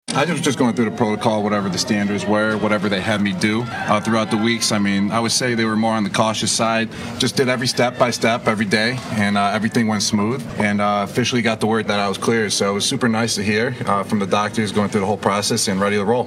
Gronkowski said that the team took every precaution and he’s ready to return to the field.